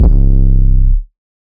808_Oneshot_Climax_C.wav